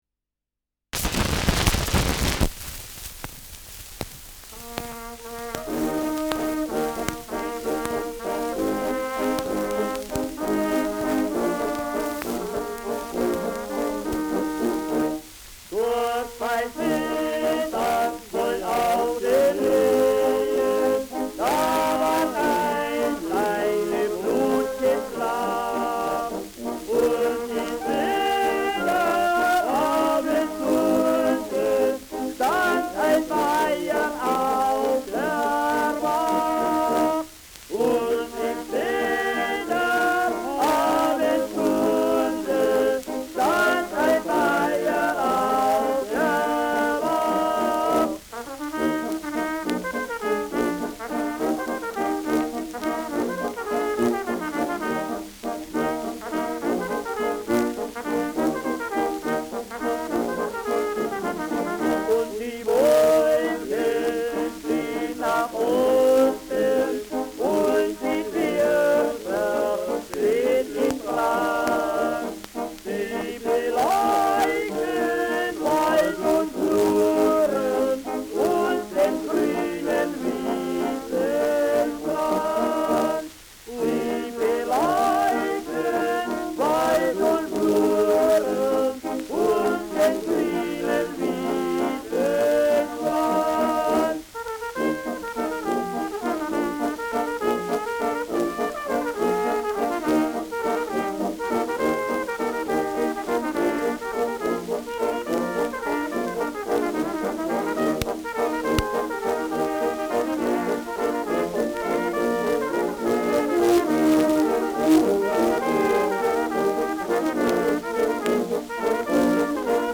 Und bei Sedan wohl auf den Höhen : Gesangs-Walzer
Schellackplatte
Stärkeres Grundrauschen : Gelegentlich leichtes bis stärkeres Knacken : Leiern : Verzerrt an lauten Stellen